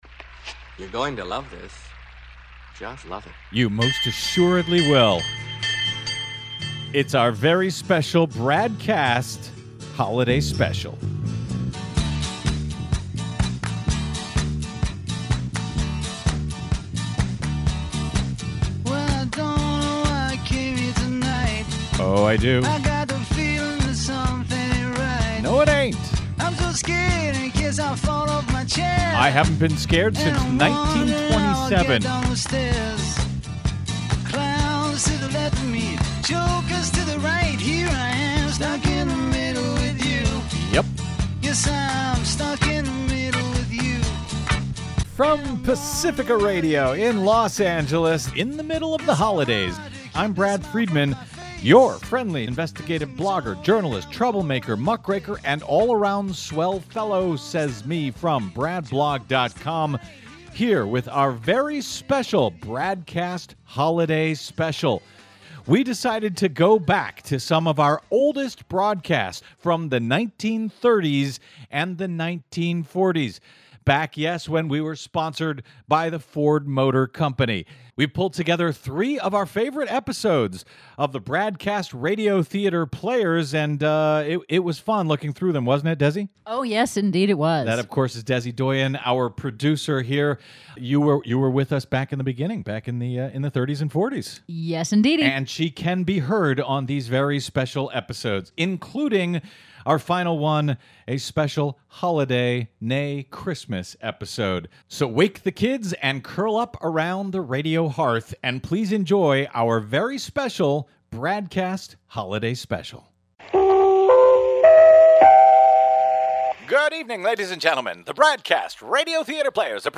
Originating on Pacifica Radio's KPFK 90.7FM in Los Angeles and syndicated coast-to-coast and around the globe!